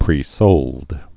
(prē-sōld)